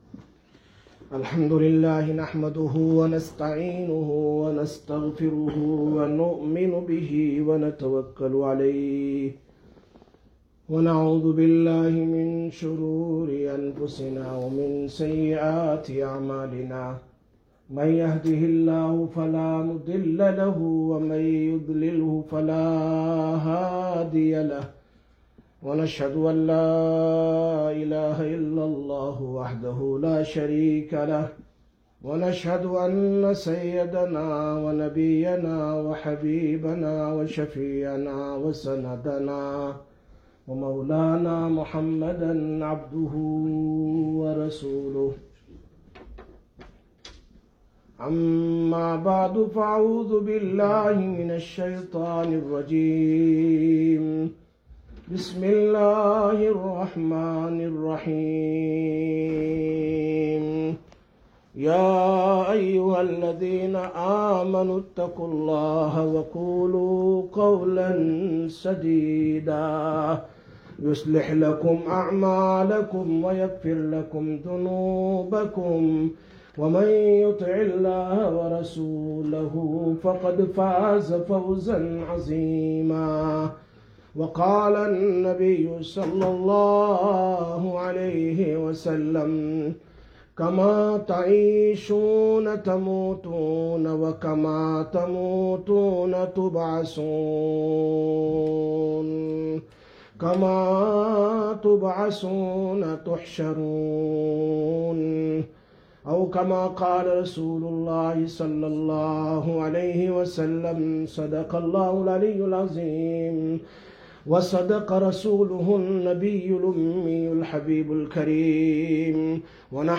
19/08/2022 Jumma Bayan, Masjid Quba